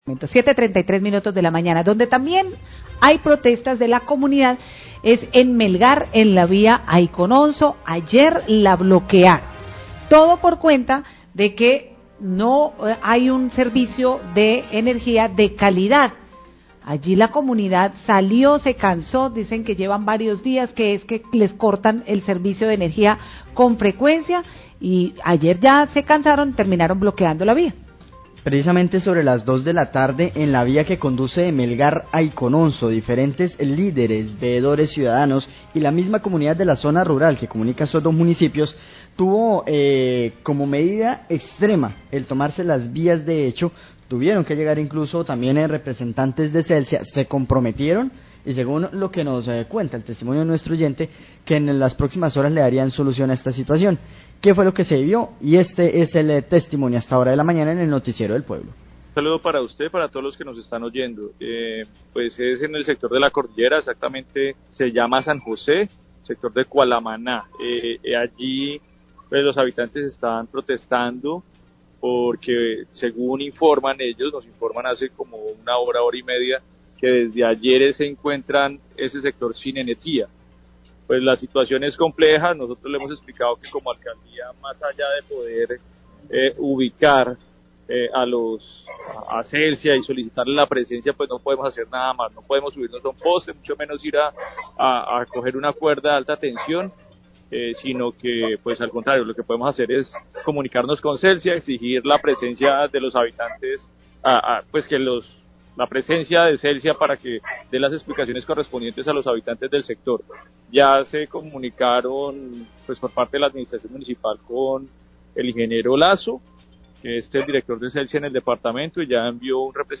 Radio
La comunidad de Melgar bloqué la vía que comunica con Icononzo en protesta por el corte prolongado de energía. Declaraciones del Secretario de Gobierno de Melgar, Ivan Dario López, quien medió en esta situación.